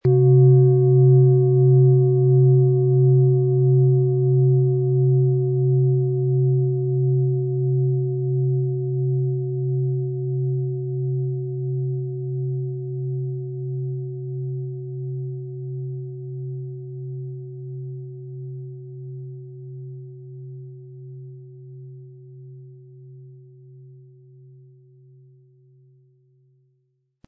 Planetenschale® Heilsame Energie erhalten & Eigene Bedürfnisse leben mit DNA-Ton & Mond, Ø 20,5 cm inkl. Klöppel
• Mittlerer Ton: Mond
Im Sound-Player - Jetzt reinhören können Sie den Original-Ton genau dieser Schale anhören.
PlanetentöneDNA & Mond
MaterialBronze